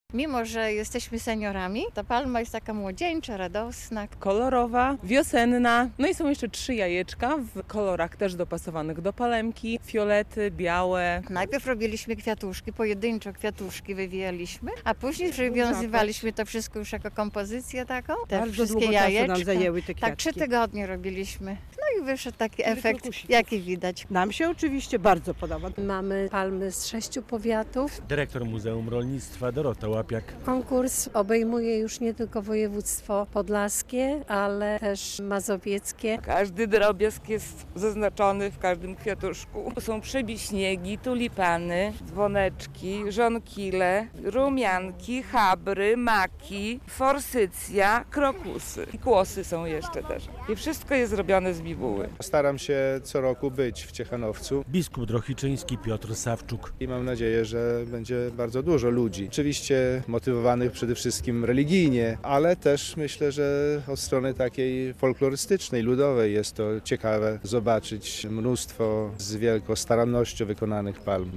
Niedziela Palmowa w Zbójnej - relacja